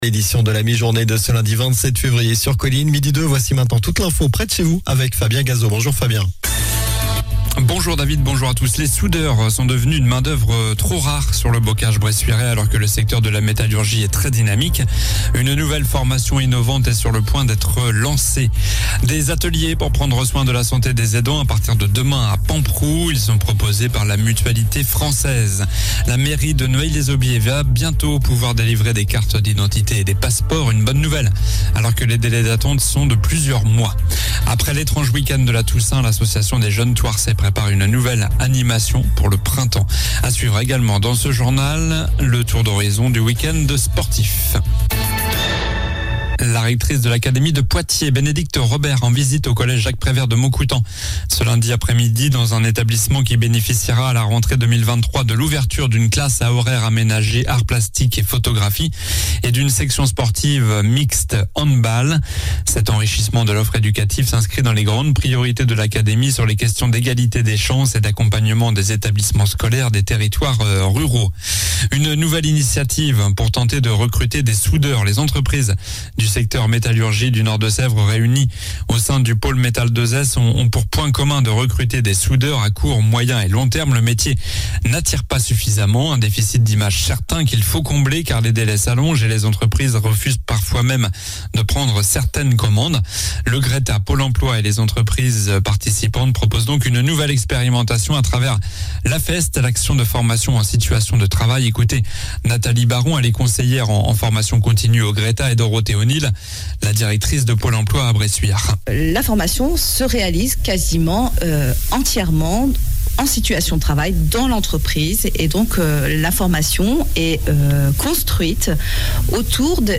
Journal du lundi 27 février (midi)